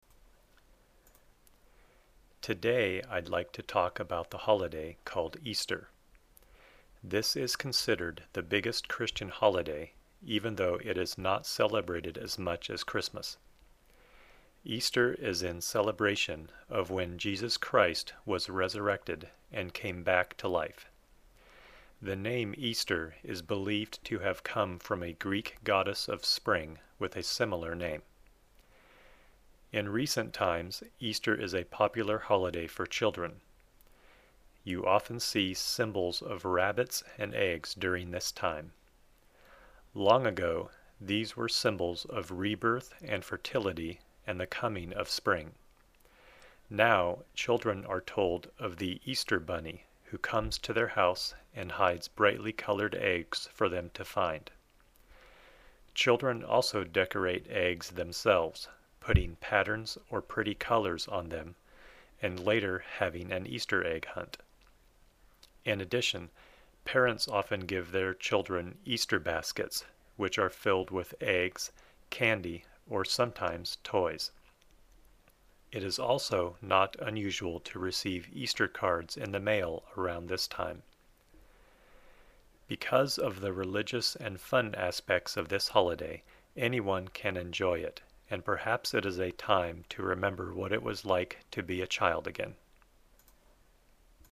音声はゆっくり録音していますので、聞いてみてくださいね。